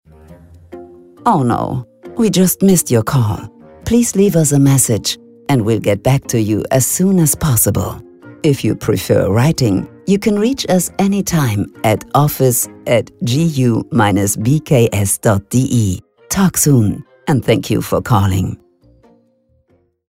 Telefonansagen mit echten Stimmen – keine KI !!!
Anruf leider verpasst ENG